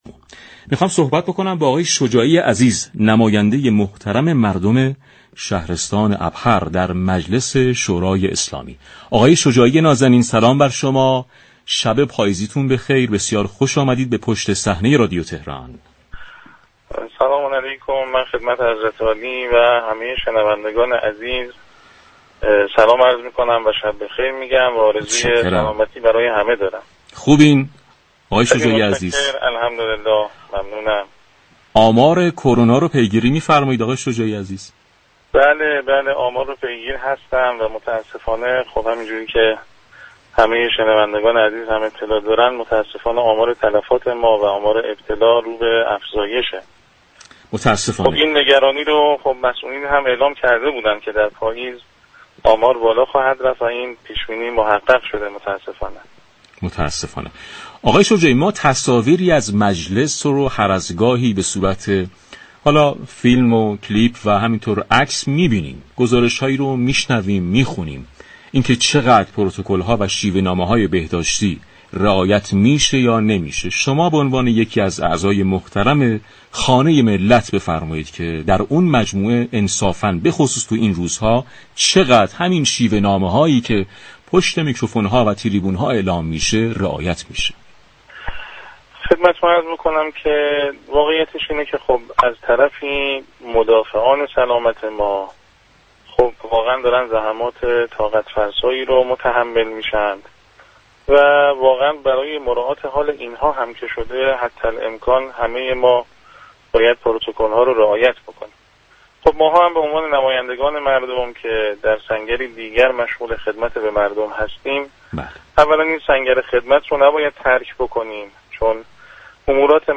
حجت الاسلام حسن شجاعی نماینده مجلس یازدهم در گفتگو با برنامه پشت صحنه رادیو تهران با تاكید بر الزام رعایت پروتكل های بهداشتی در مجلس گفت: در مجلس تمامی نمایندگان فاصله اجتماعی و استفاده از ماسك را رعایت می كنند .